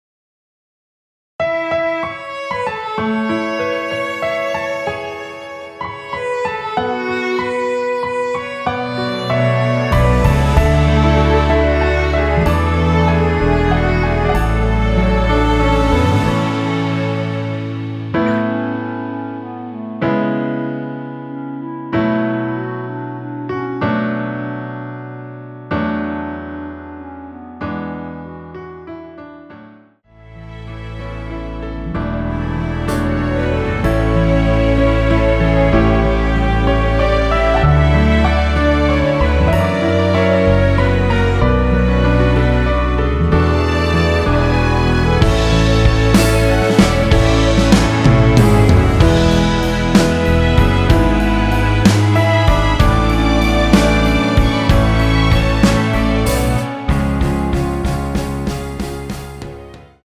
원키에서(-2)내린 1절후 후렴으로 진행되는 멜로디 포함된 MR입니다.
앞부분30초, 뒷부분30초씩 편집해서 올려 드리고 있습니다.
중간에 음이 끈어지고 다시 나오는 이유는